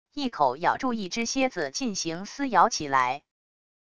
一口咬住一只蝎子进行撕咬起来wav音频